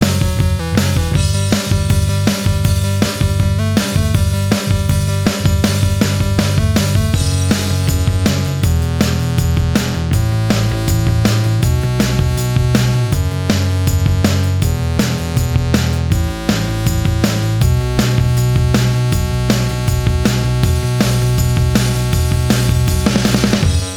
No Guitars Pop (2000s) 3:32 Buy £1.50